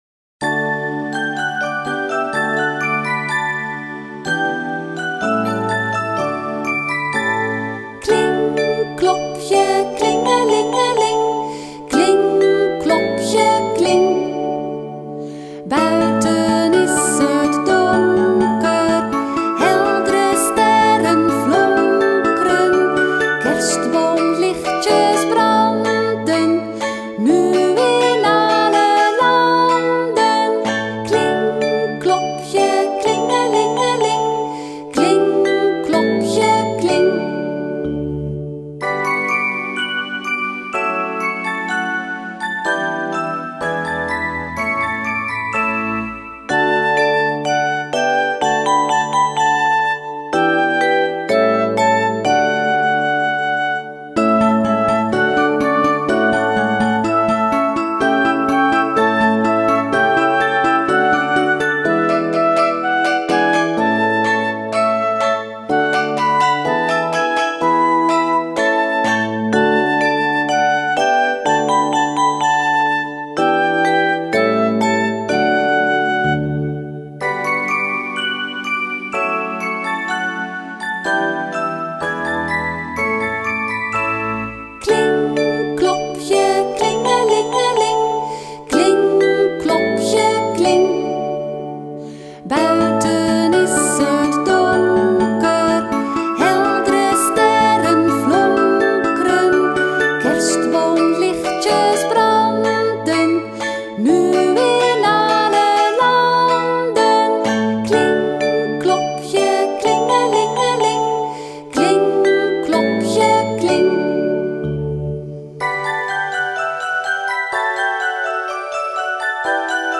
Categorie:liedjes en versjes